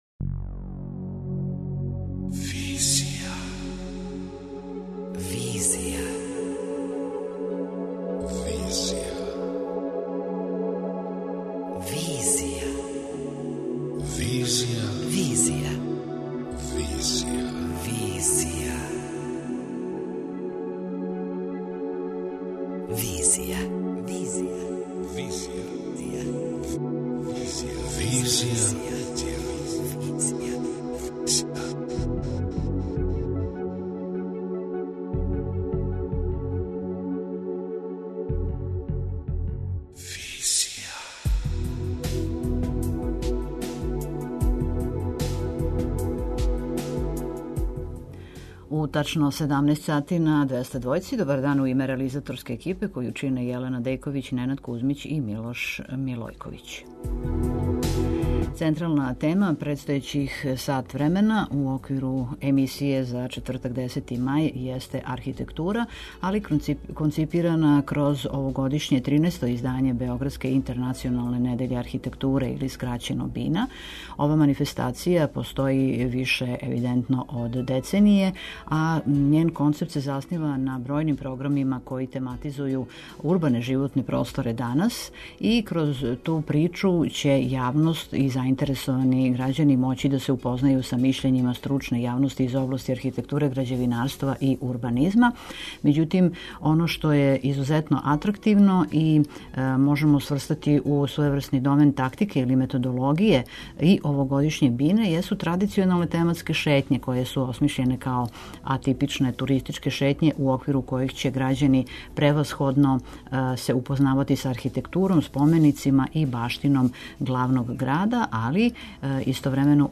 преузми : 26.84 MB Визија Autor: Београд 202 Социо-културолошки магазин, који прати савремене друштвене феномене.